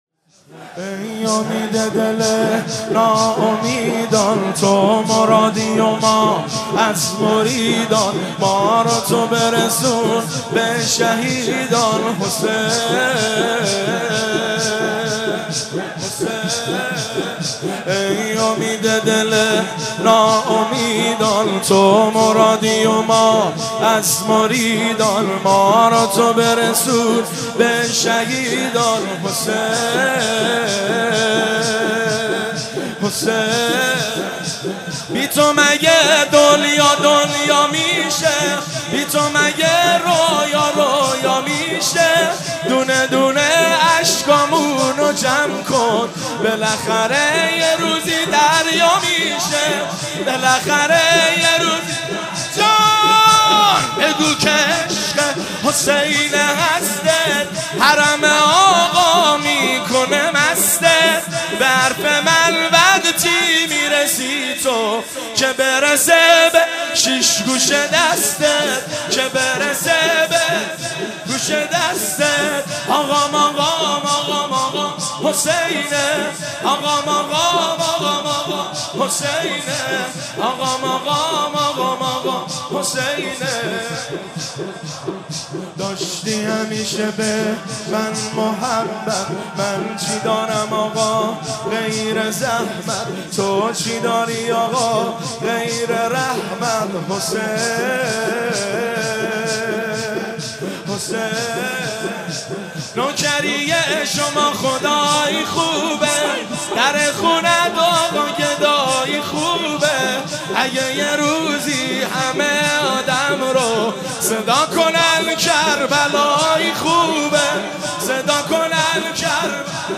شب تاسوعا محرم 1392